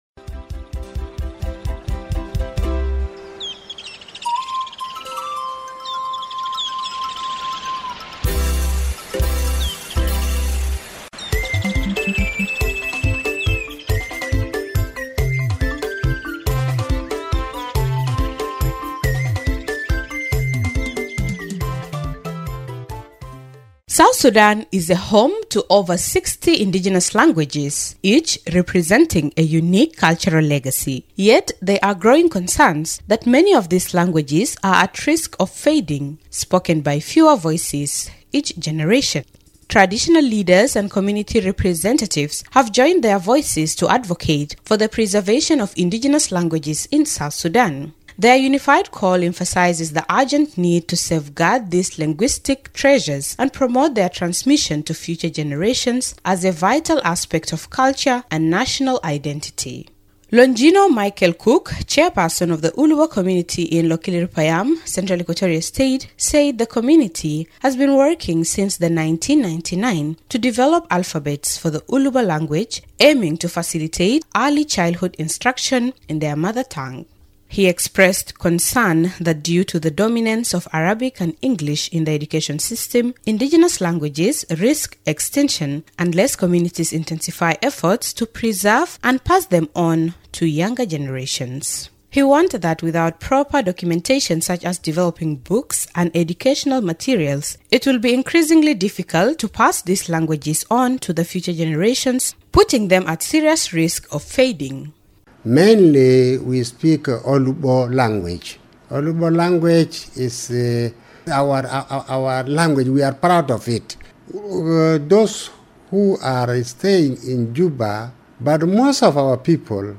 FEATURE-STORY-ON-PRESERVING-INDIGNEOUS-LANGAUAGES-2.mp3